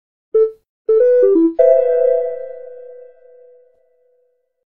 공항의 사운드 로고